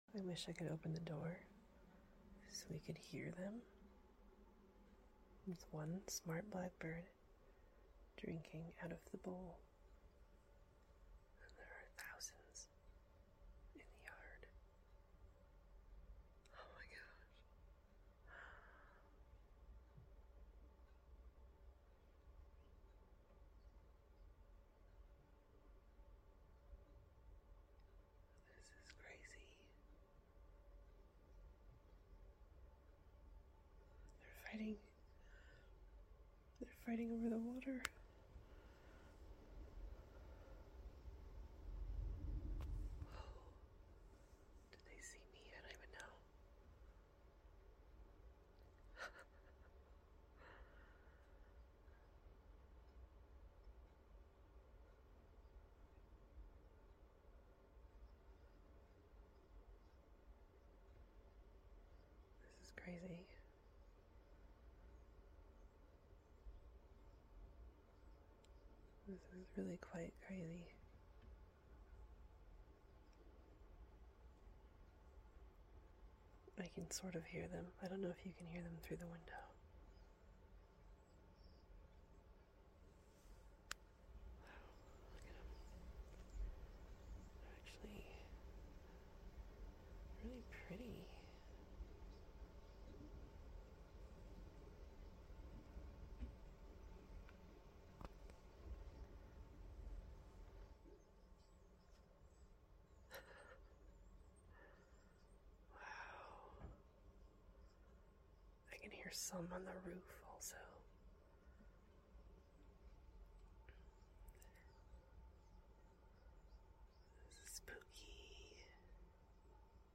so many blackbirds 😳😳 sound effects free download